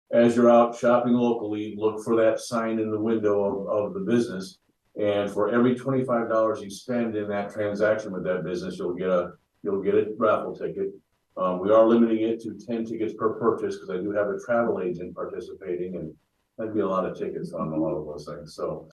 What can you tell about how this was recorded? appearing at the Harlan City Council meeting